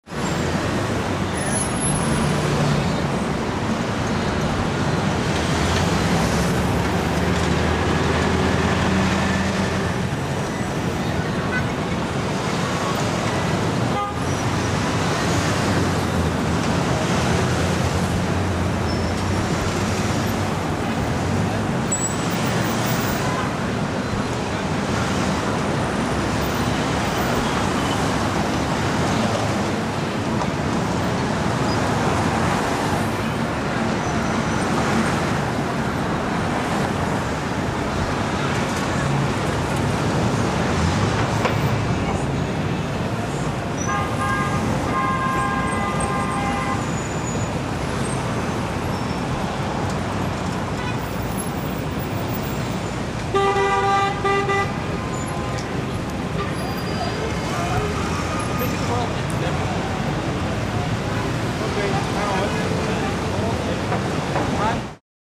CIUDAD CITY TRAFFIC
Ambient sound effects
Ciudad_city_traffic.mp3